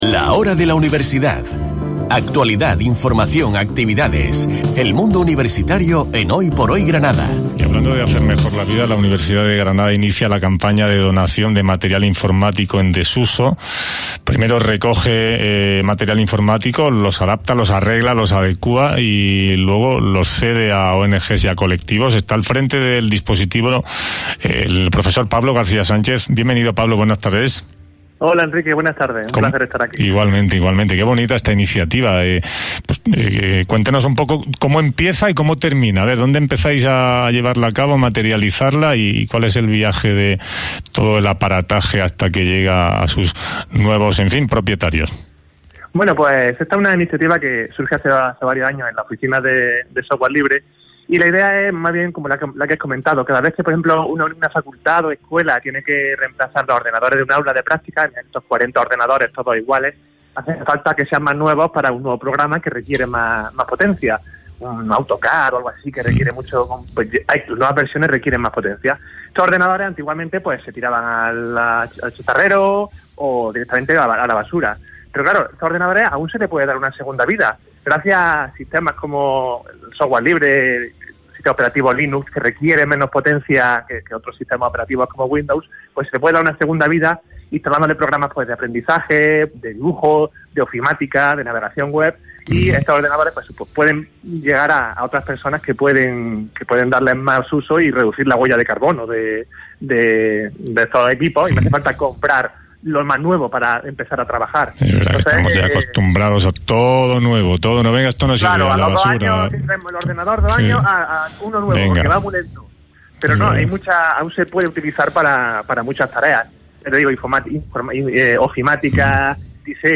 entrevistan